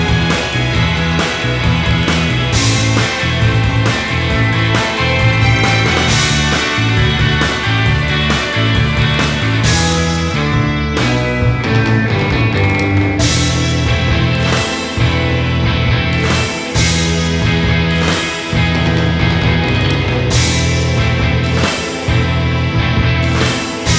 Minus Main Guitar Indie / Alternative 3:39 Buy £1.50